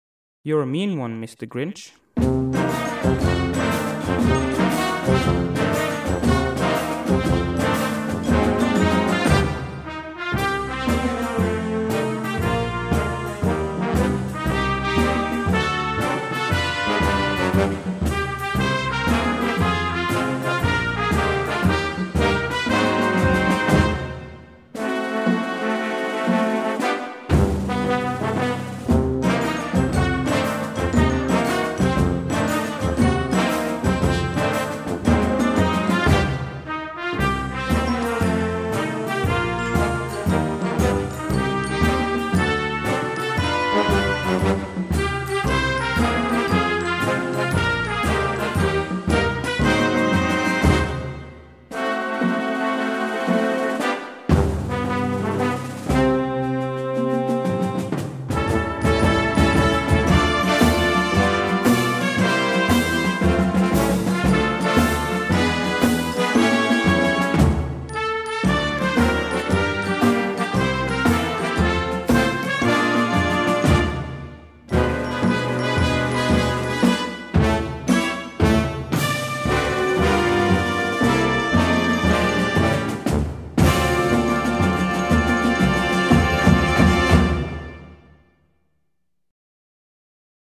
Einfach für kleinere Ensembles.
1:18 Minuten Besetzung: Blasorchester Tonprobe